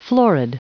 Prononciation du mot florid en anglais (fichier audio)
Prononciation du mot : florid